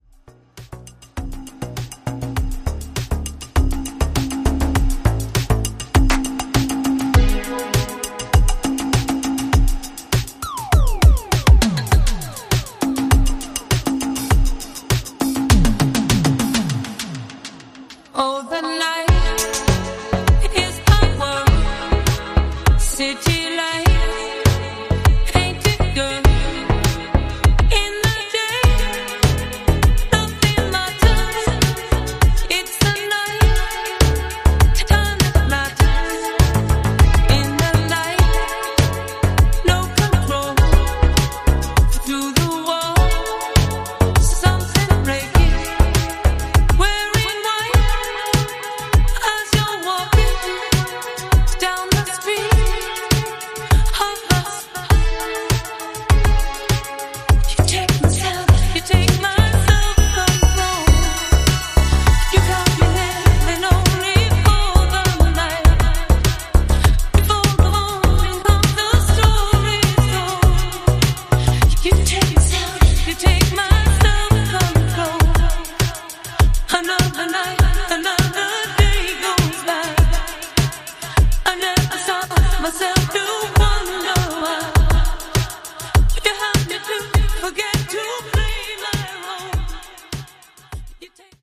ジャンル(スタイル) NU DISCO / ITALO DISCO / EDITS